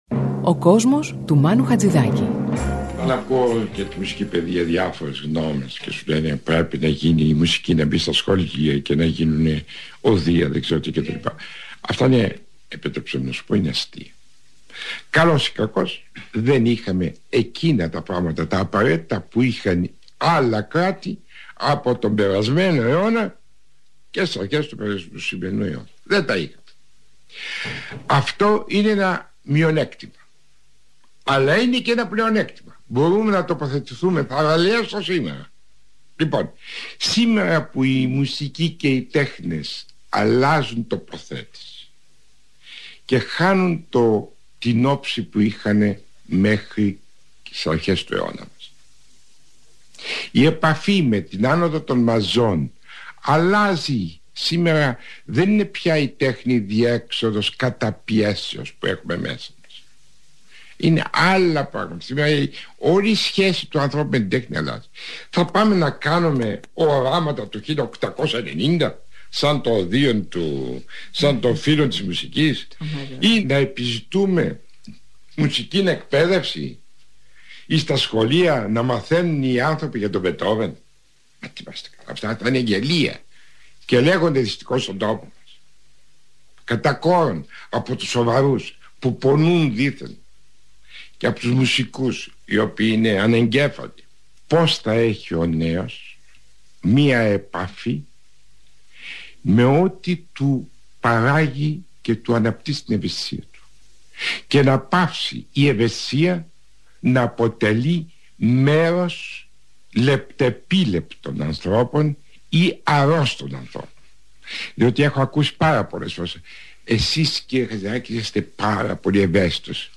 Ακούμε τη φωνή του Μάνου Χατζιδάκι και μπαίνουμε στον κόσμο του.